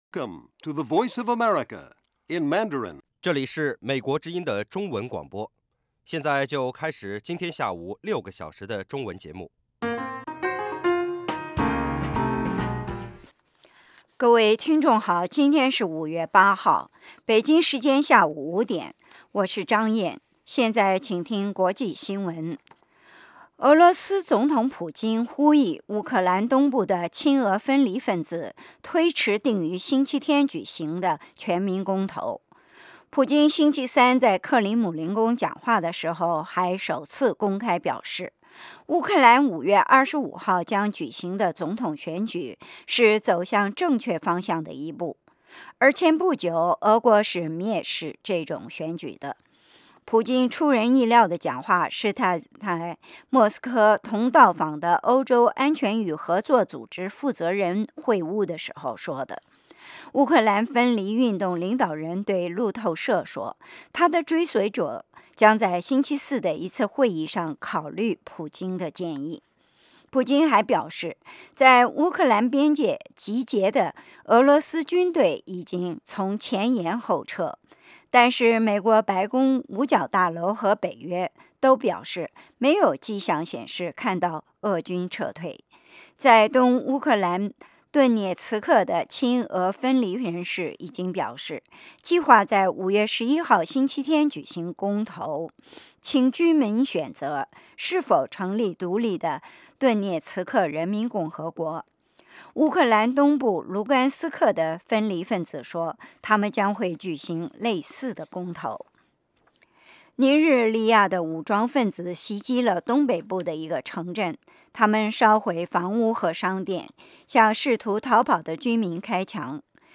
晚5-6点广播节目
国际新闻 英语教学 社论 北京时间: 下午5点 格林威治标准时间: 0900 节目长度 : 60 收听: mp3